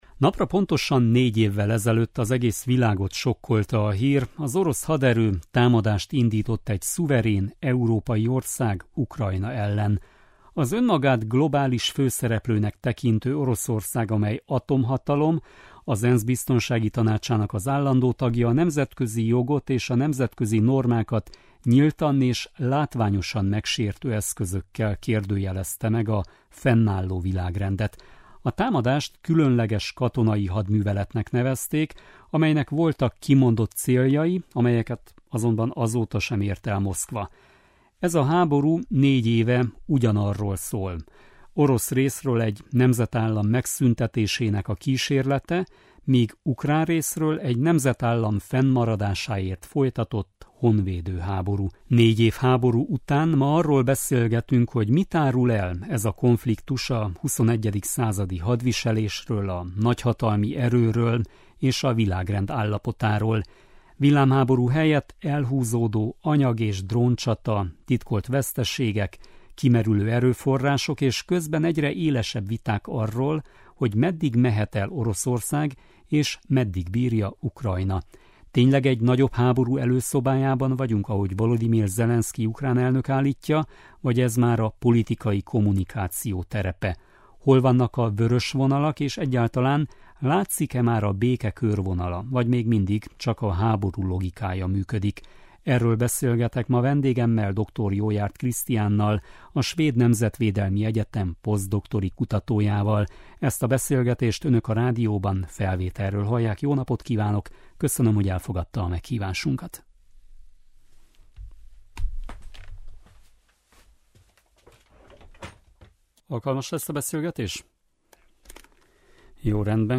Ez a háború négy éve ugyanarról szól: orosz részről egy nemzetállam megszüntetésének kísérlete, míg ukrán részről egy nemzetállam fennmaradásáért folytatott honvédő háború. Négy év háború után arról beszélgetünk, hogy mit árul el ez a konfliktus a 21. századi hadviselésről, a nagyhatalmi erőről és a világrend állapotáról.